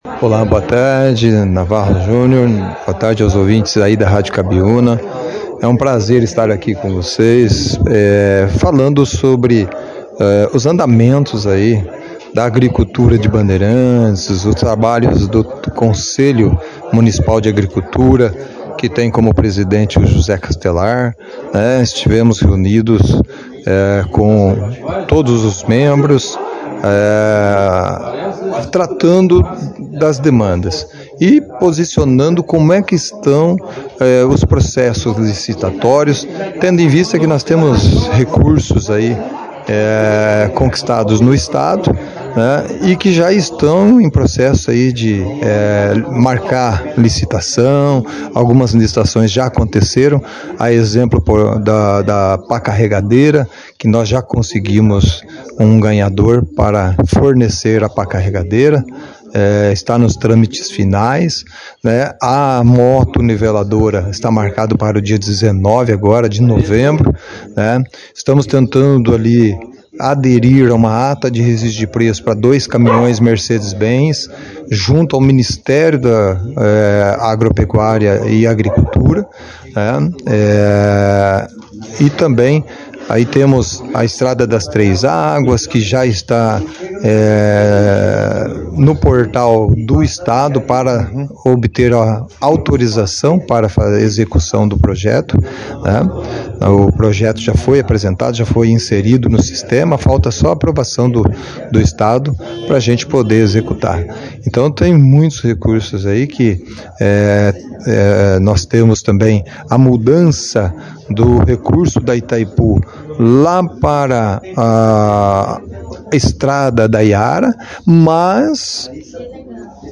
O encontro foi destaque na 2ª edição do Jornal Operação Cidade, desta sexta-feira, dia 14, com uma entrevista em que o prefeito resumiu os principais assuntos tratados junto ao conselho.